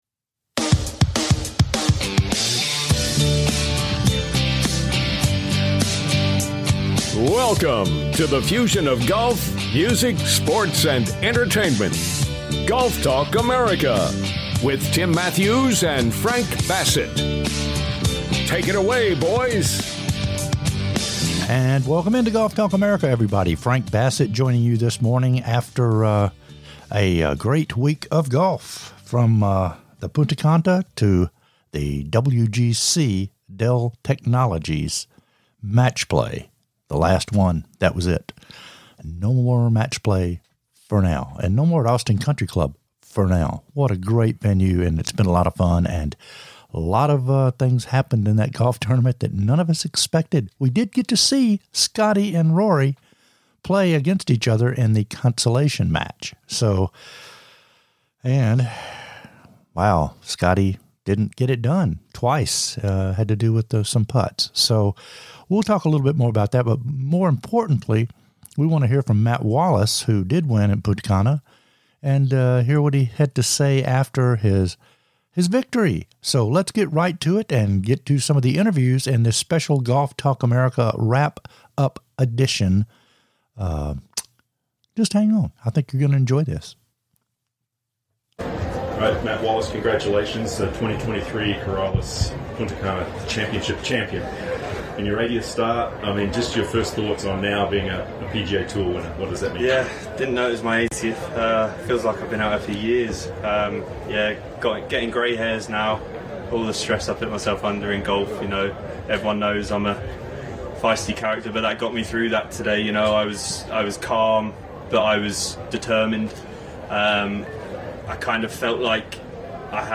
"LIVE" INTERVIEWS FROM THIS WEEK ON THE PGA TOUR & THE WGC